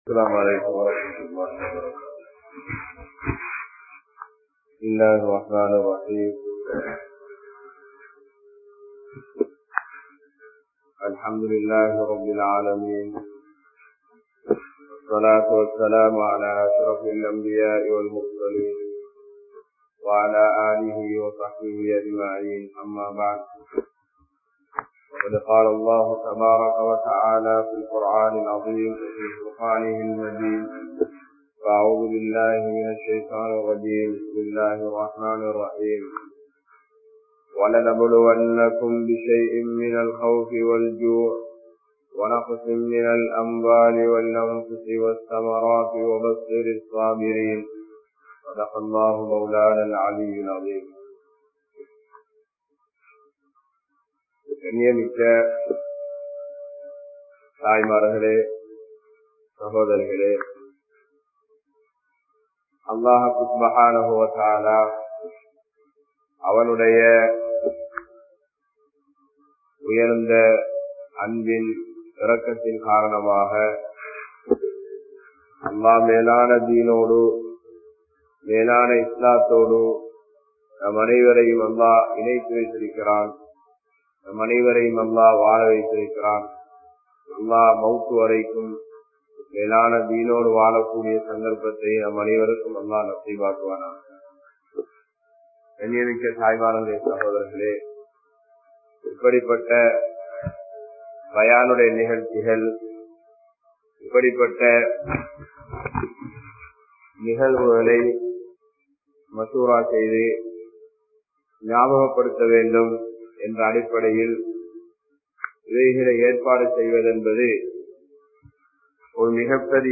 Marumaithaan Emathu Ilakku (மறுமைதான் எமது இலக்கு) | Audio Bayans | All Ceylon Muslim Youth Community | Addalaichenai
Dhiwlana Jumua Masjidh